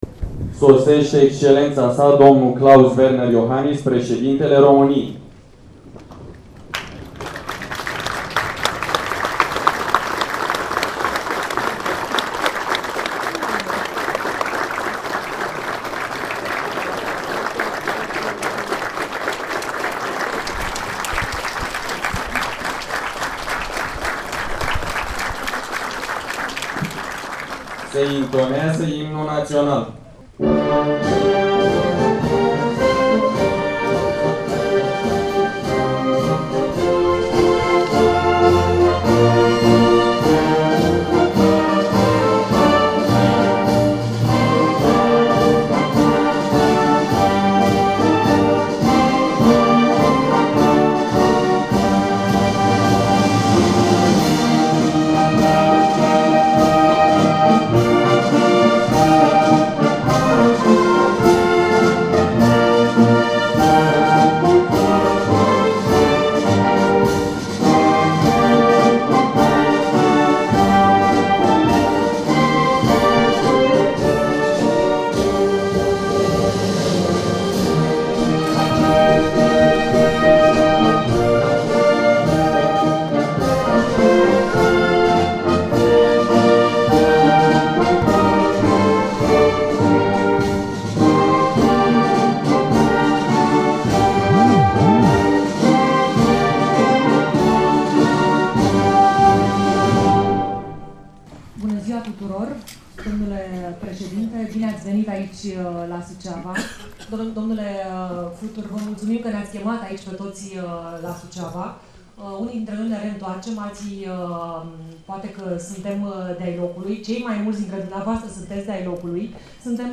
discursul-lui-klaus-iohannis-la-suceava-2017.mp3